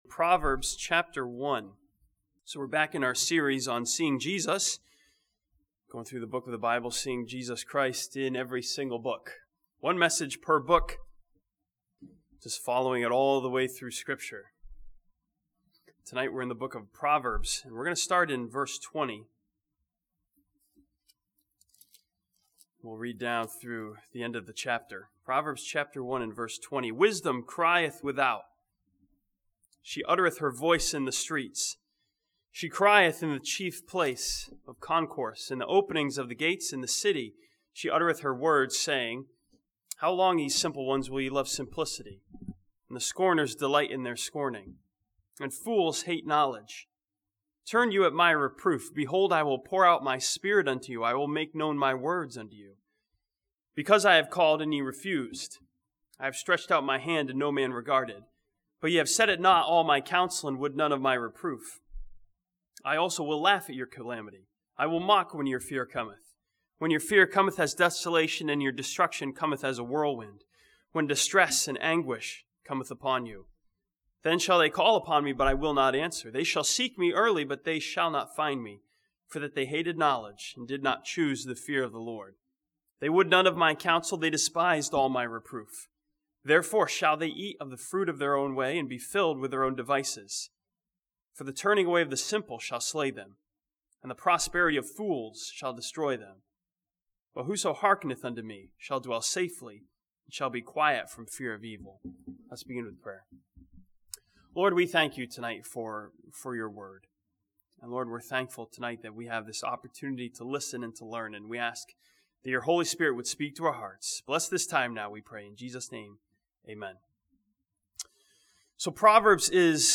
This sermon from Proverbs chapter one sees Jesus Christ as our Wisdom who confronts us to turn at His reproof.